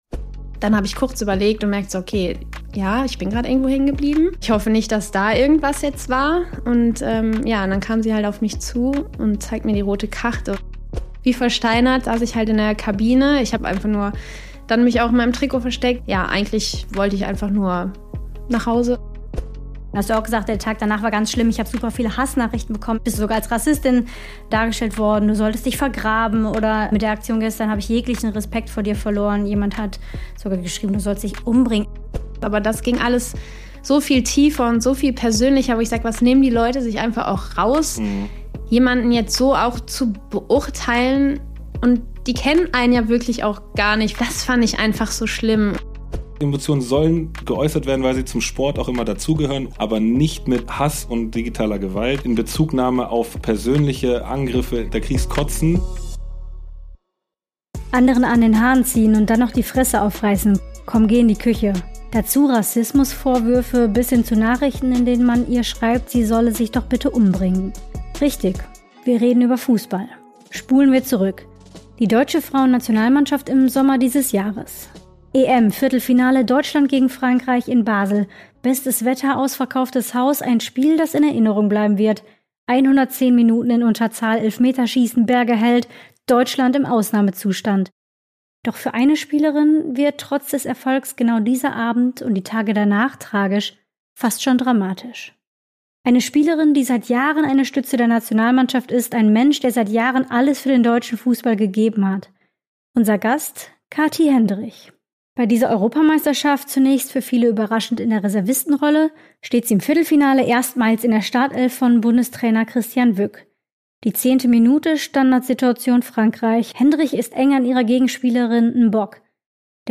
In dieser Episode spricht Nationalspielerin Kathy Hendrich ausführlich über den Moment, der ihre EM 2025 verändert hat: die frühe rote Karte im Viertelfinale gegen Frankreich.